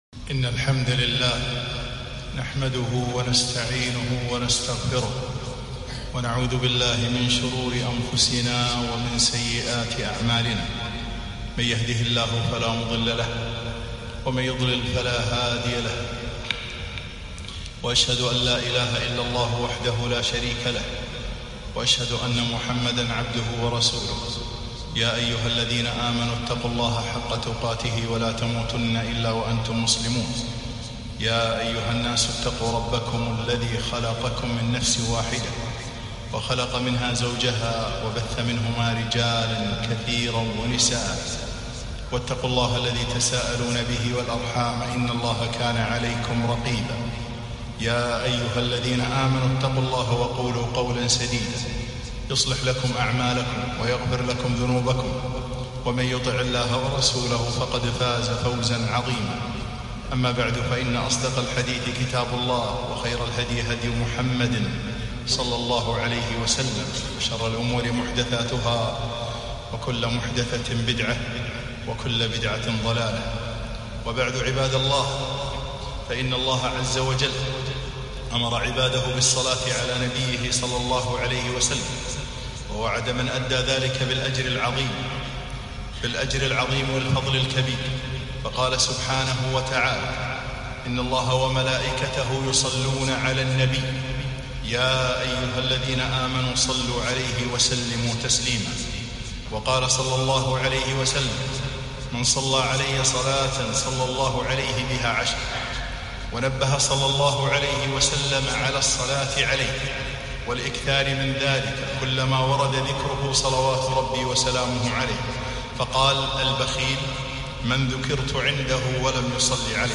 خطبة من فضائل الصلاة على النبي ﷺ